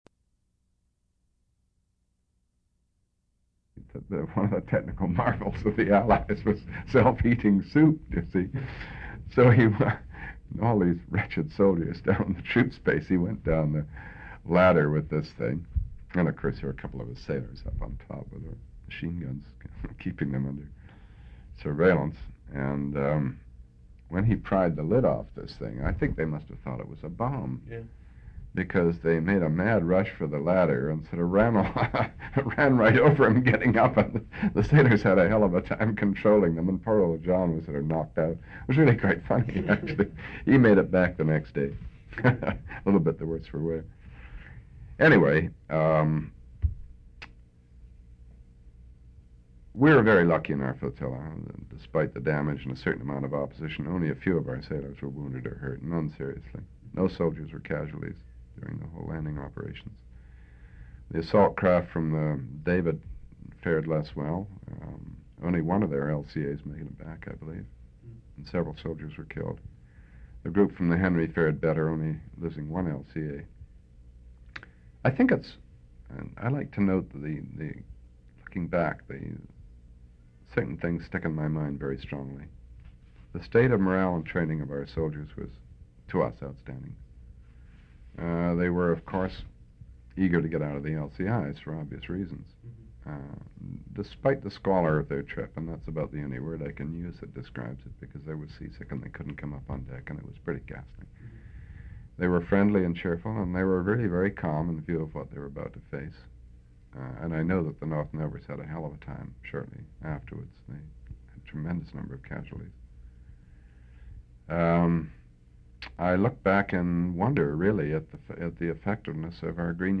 An interview/narrative